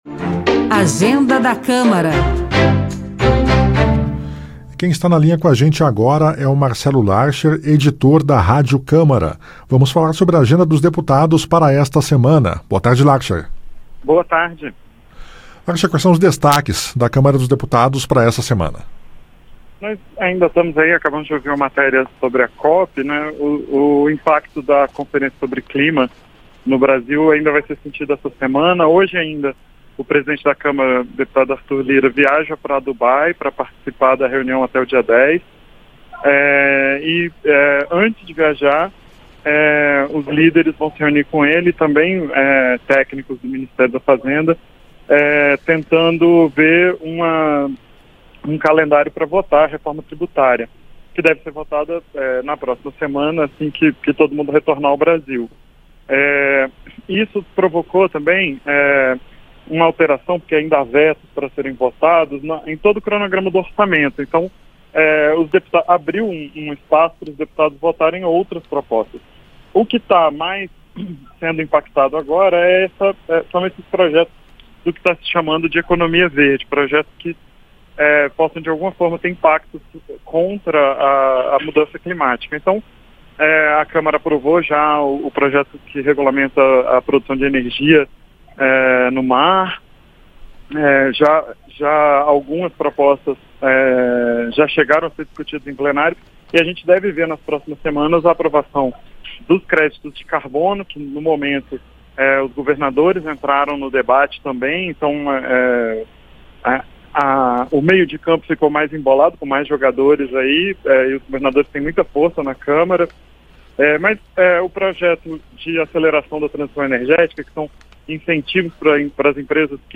Programa jornalístico que trata da agenda do Congresso Nacional, com entrevistas, comentários e reportagens sobre os principais assuntos em debate e em votação.